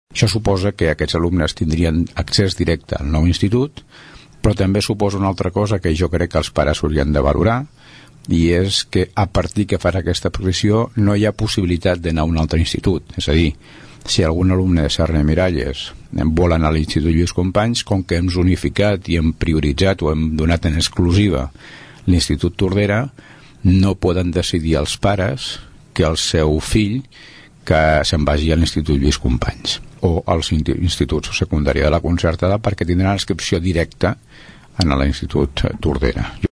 Escoltem l’Alcalde de Tordera, Joan Carles Garcia.